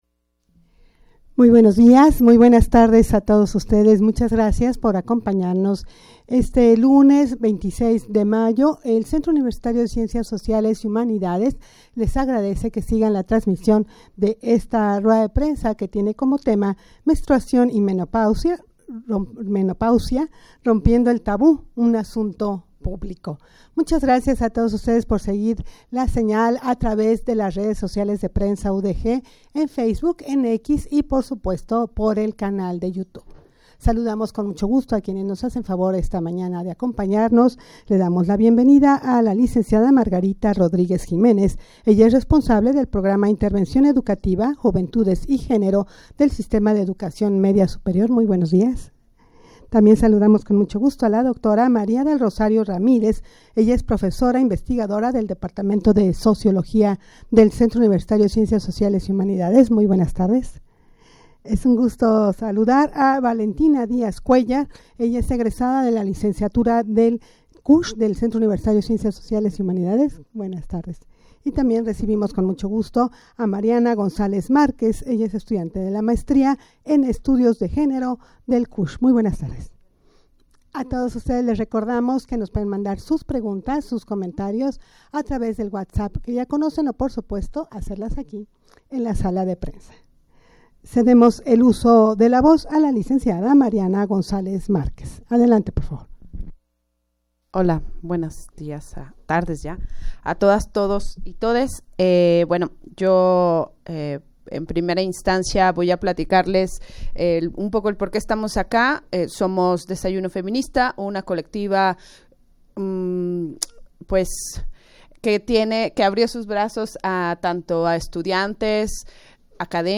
Audio de la Rued de Prensa
rueda-de-prensa-menstruacion-y-menopausia-rompiendo-el-tabu-un-asunto-publico.mp3